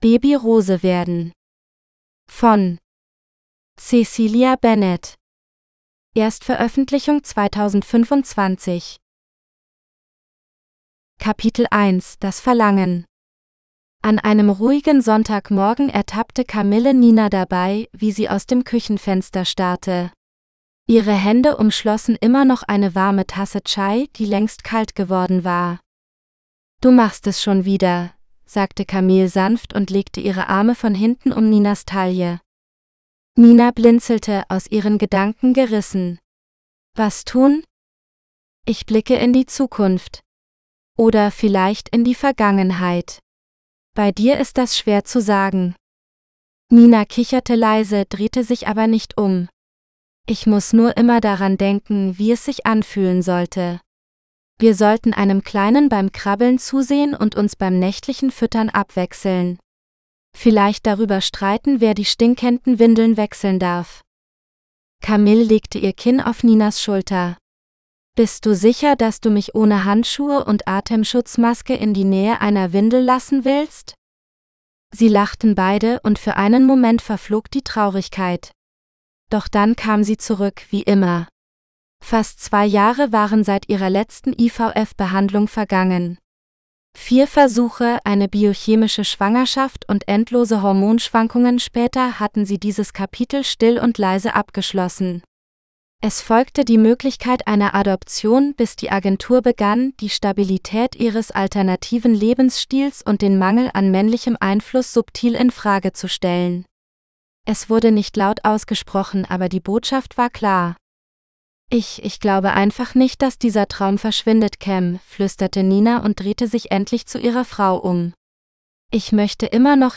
Baby Rose werden GERMAN (AUDIOBOOK – female): $US2.99